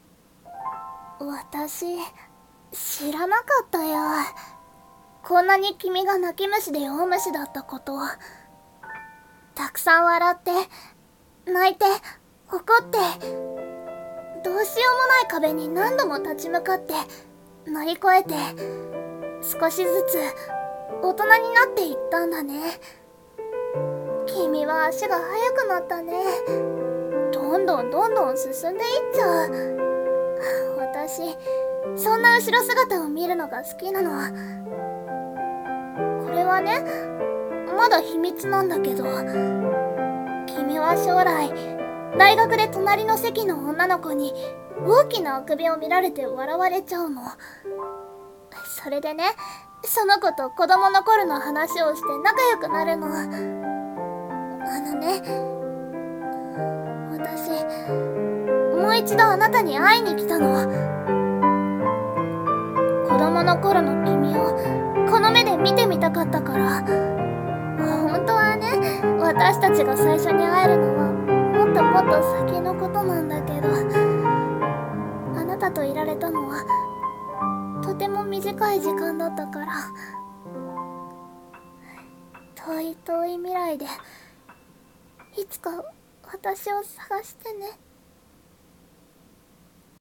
【声劇】未来から来た彼女